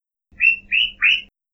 bip_04.wav